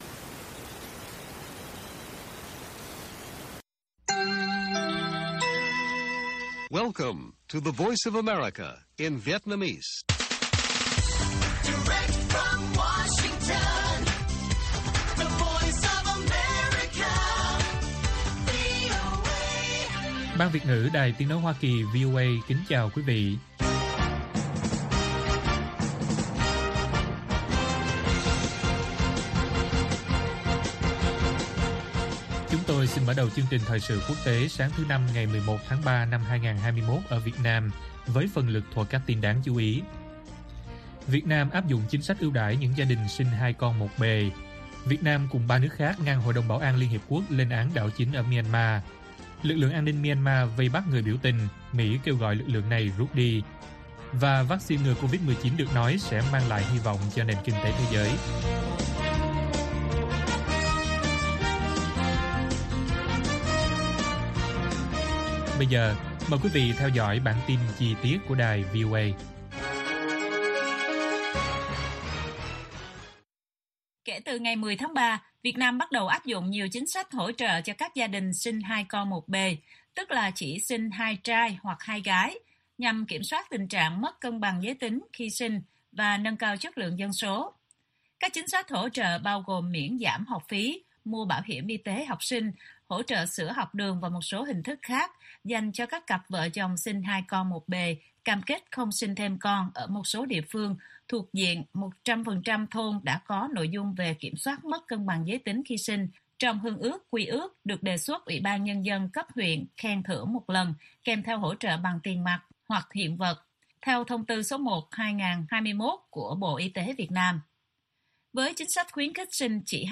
Bản tin VOA ngày 11/3/2021